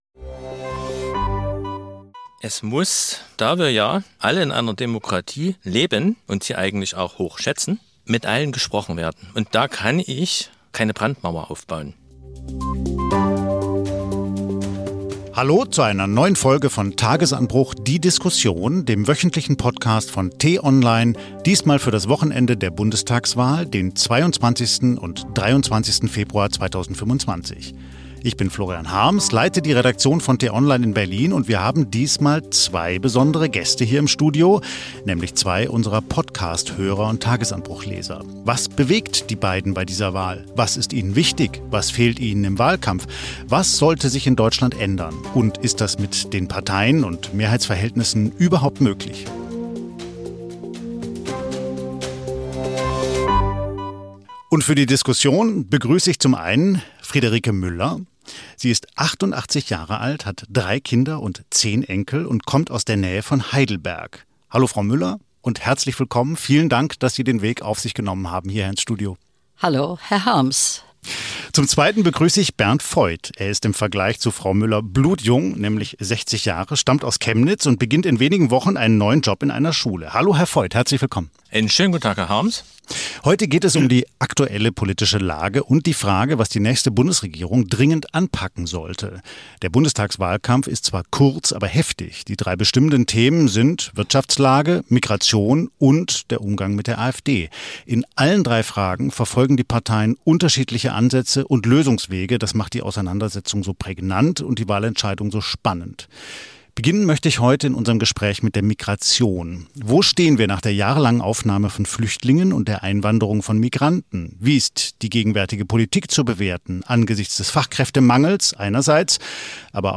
Bundestagswahl: Übersehen wir die echten Probleme? (Hörer-Diskussion)